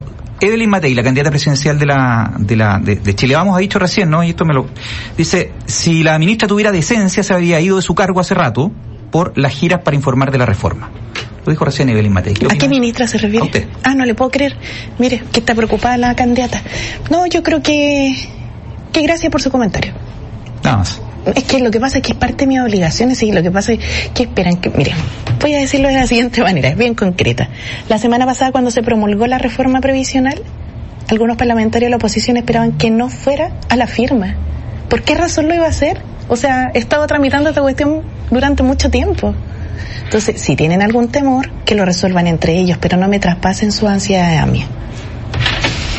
En conversación con Radio Bío Bío de Concepción, la ministra Jeannette Jara (PC) respondió la reciente crítica...